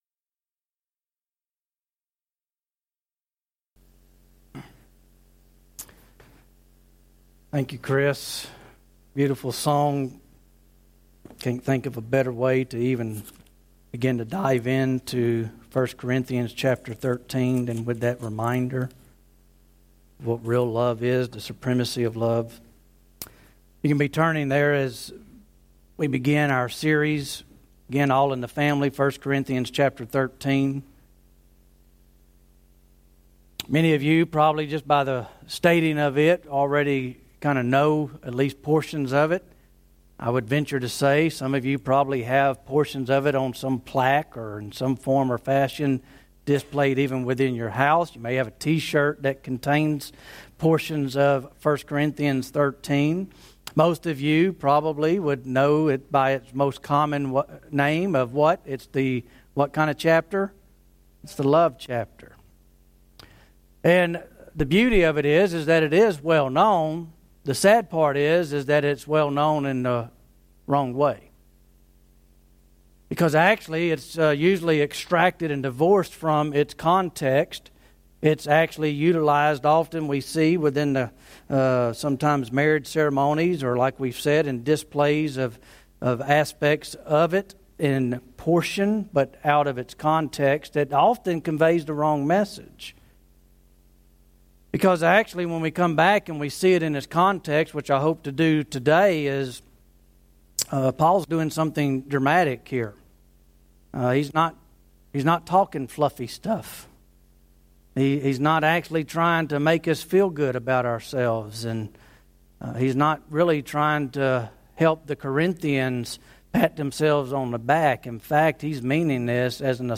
Sermons 2016-2017